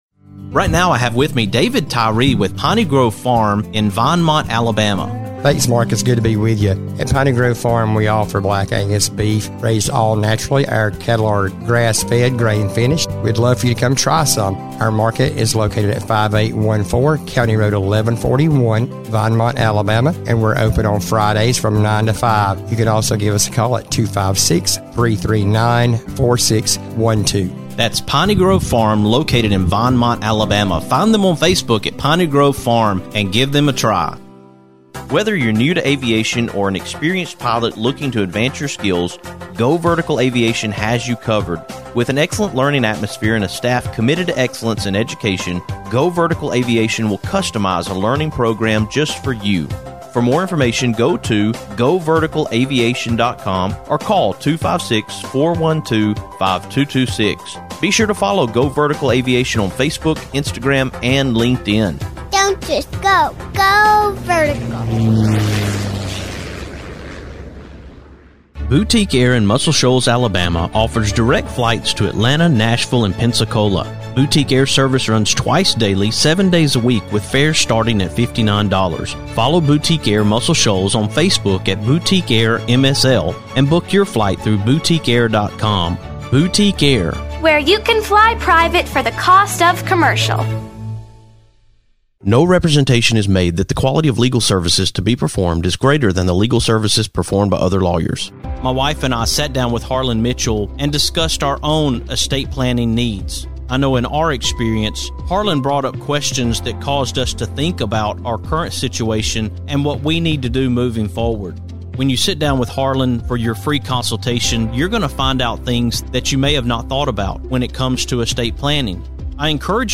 I'm having a conversation